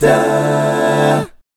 1-DMI7  AA-L.wav